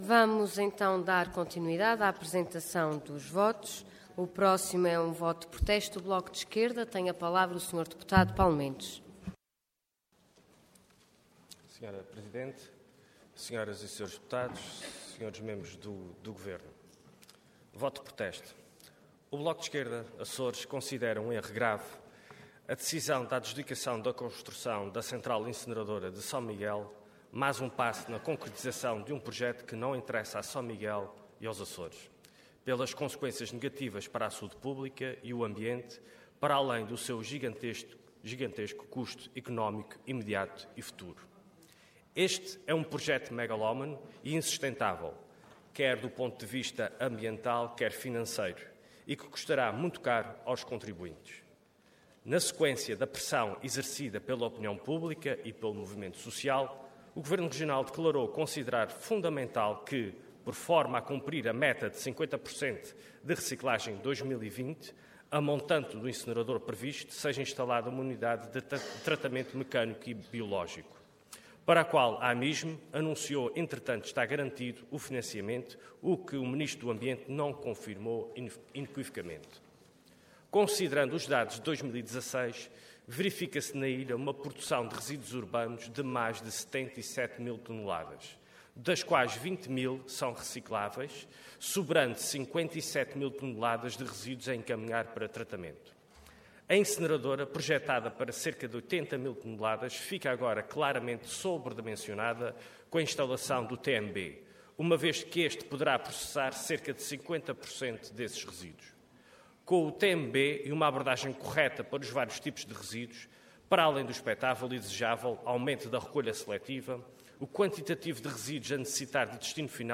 Intervenção Voto de Protesto Orador Paulo Mendes Cargo Deputado Entidade BE